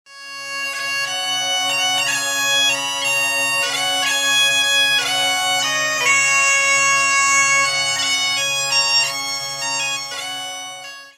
Catégorie Bruitages